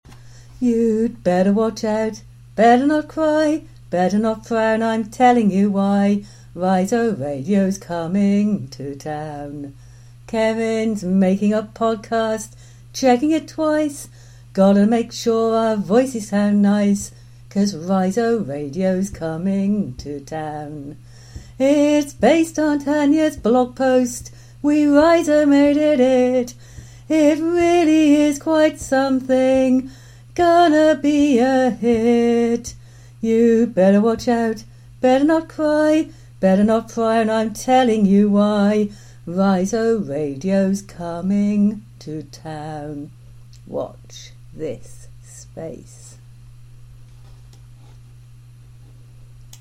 'radio play' (streamed not broadcast) consisting of an imaginary dialogue between a rhizomatic learner and an arborescent educator.
One version also offered an amateur rendition of the song  'Santa Claus Is Coming To Town' rendered as ''RhizoRadio is Coming To Town -- here).